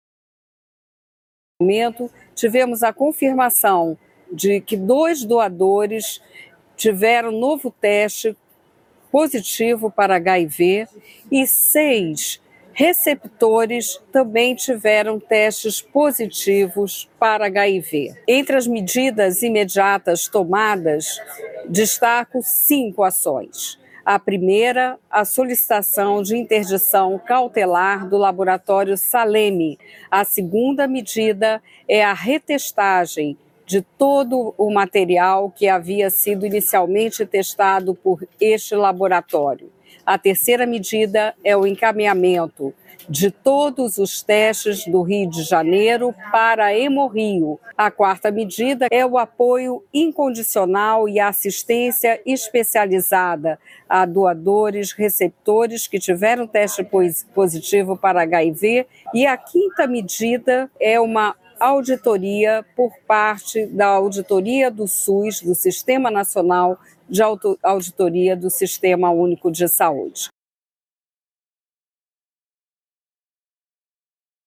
Em entrevista a Globonews, a ministra da Saúde, Níssia Trindade, comentou sobre o caso dos transplantes contaminados com HIV